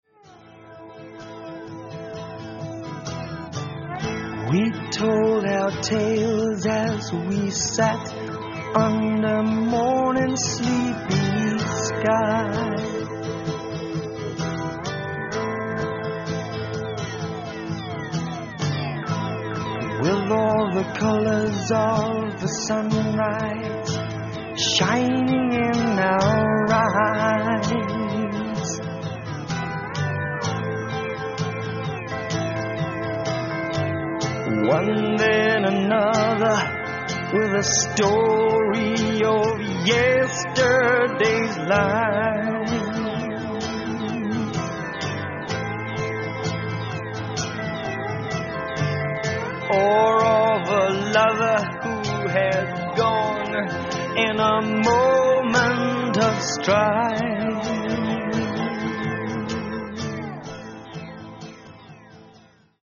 Lansdowne Studios, London
Lead Vocals
Keyboards, Guitars, Moog Synthesizer
Drums and Percussion
Bass